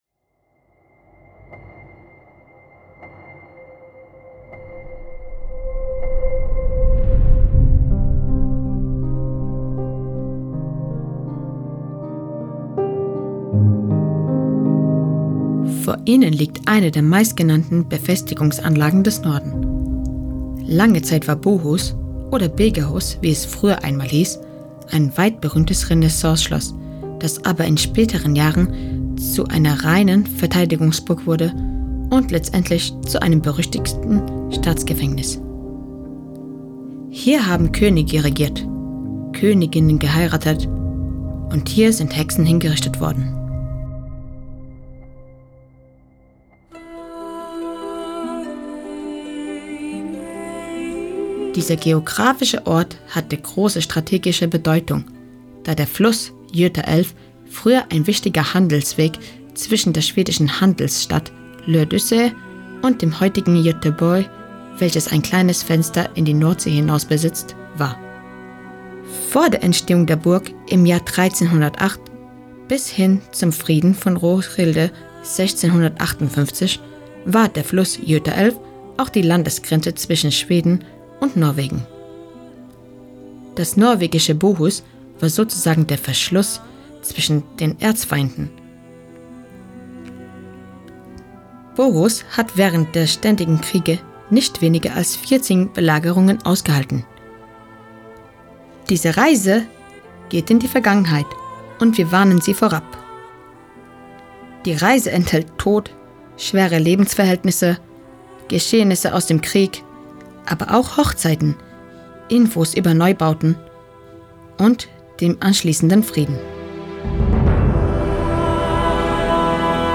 Erzählerstimme
Mit diesem Audioguide tauchst du ein in die dramatische Geschichte der Festung Bohus – von mittelalterlichen Machtspielen und königlichen Hochzeiten bis hin zu blutigen Belagerungen, Gefängniszellen und Hexenprozessen.